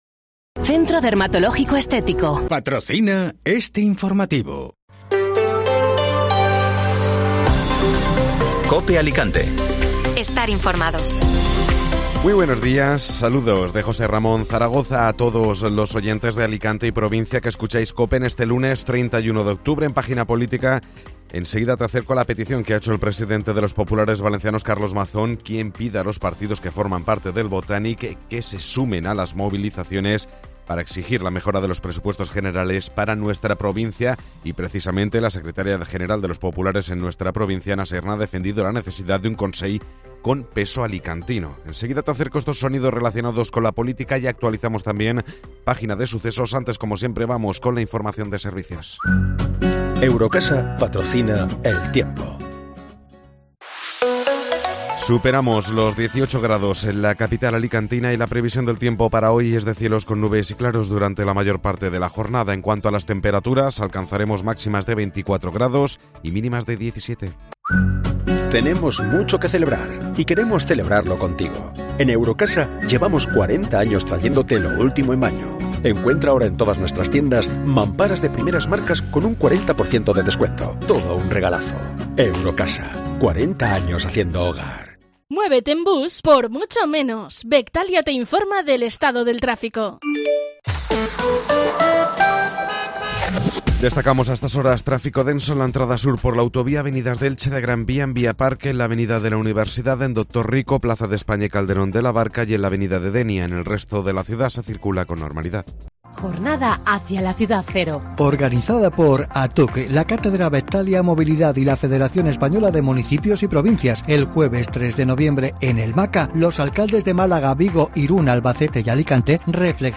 Informativo Matinal (Lunes 31 de Octubre)